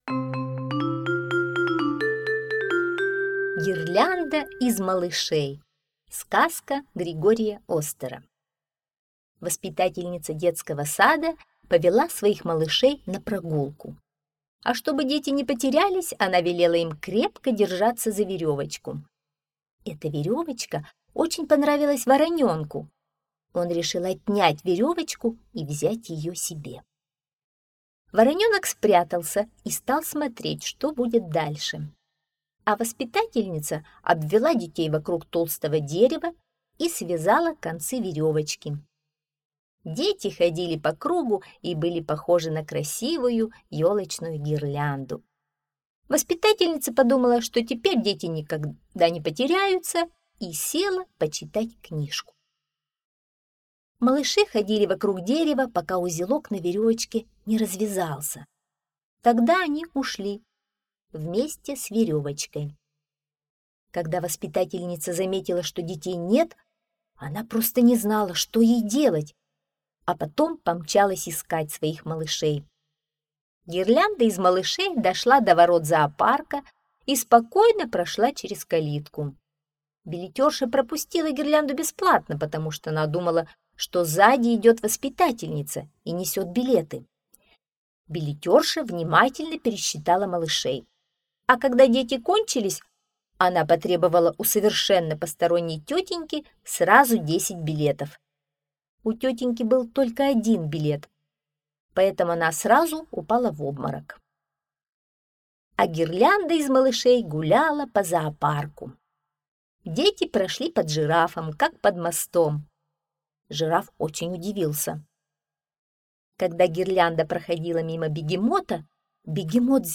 Гирлянда из малышей — аудиосказка Григория Остера. Короткая смешная история про детишек, которые ушли от воспитательницы гулять в зоопарк.